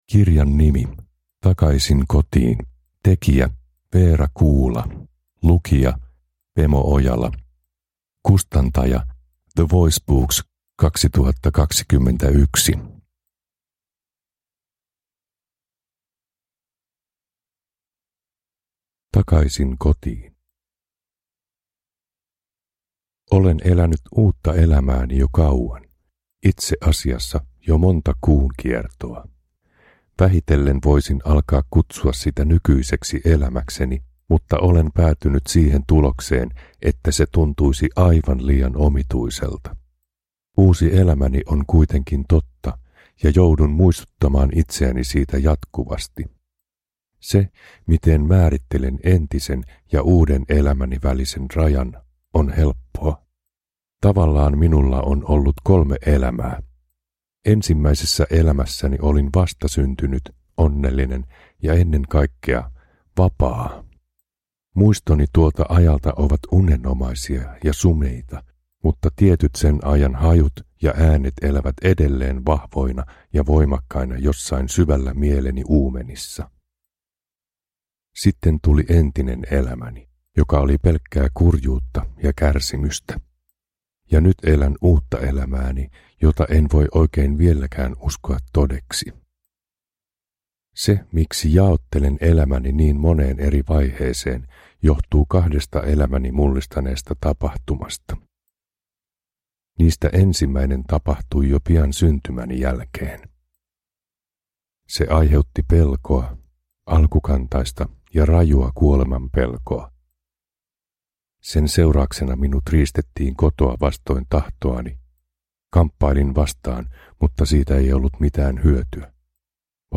Takaisin Kotiin – Ljudbok